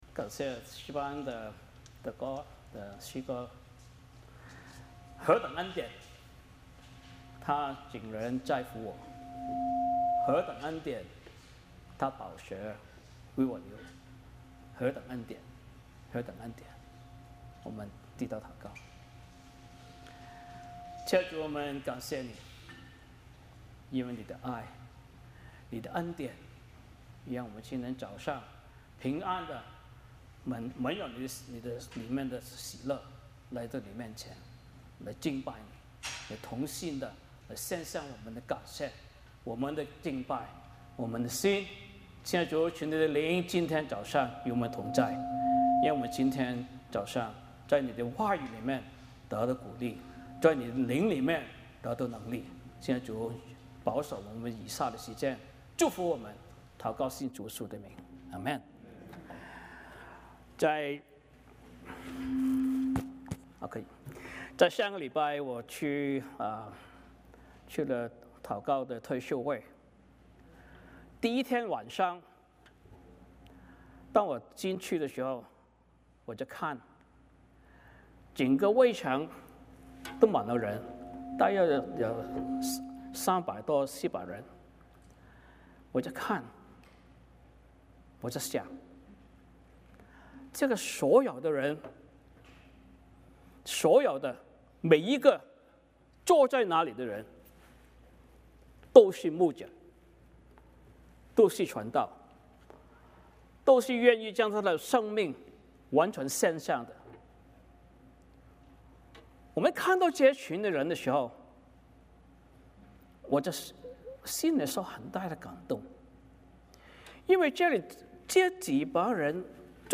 使徒行传 8:26-40 Service Type: 主日崇拜 欢迎大家加入我们的敬拜。